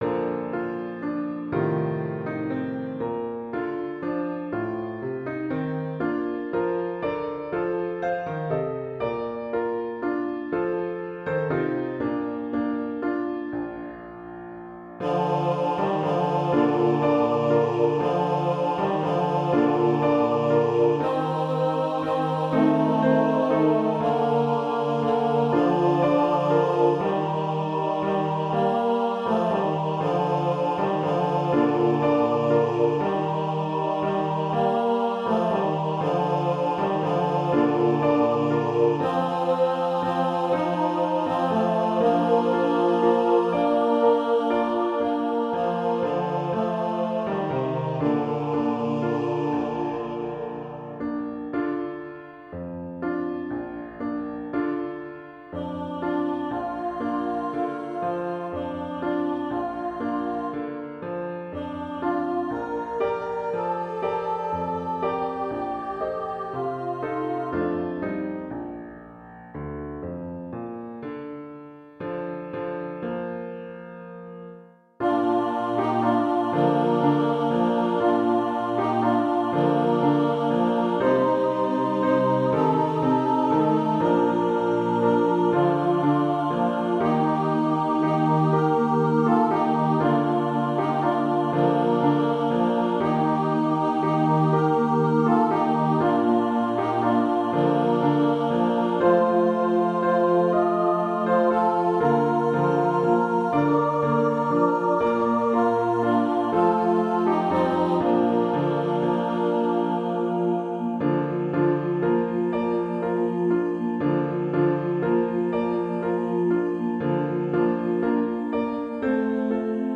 I also put in an annoying coo-coo bird.